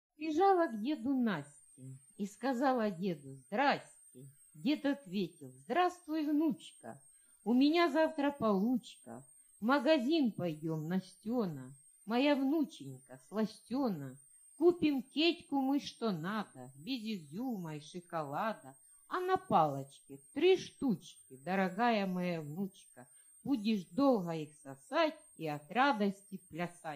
Стих начитан автором.